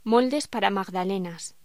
Locución: Moldes para magdalenas
voz